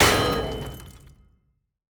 Damaged Sound.ogg